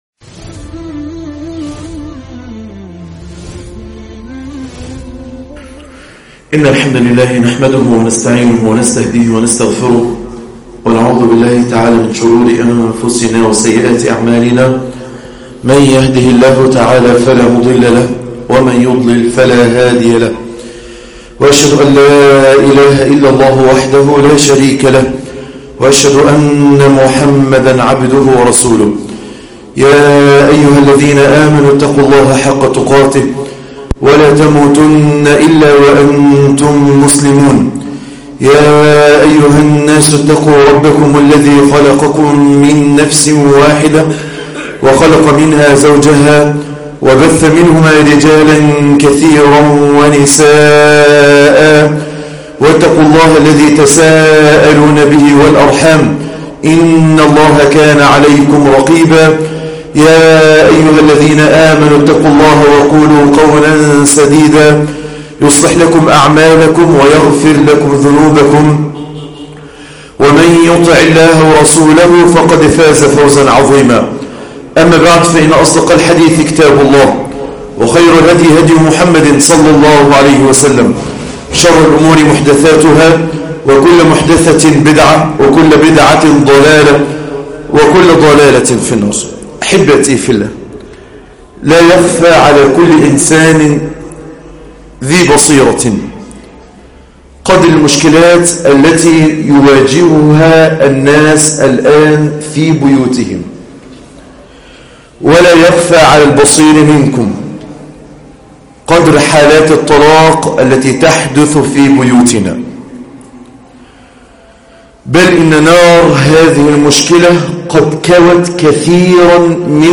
الإحتواء العاطفي في البيت النبوي ( 24/11/2017)خطبة الجمعة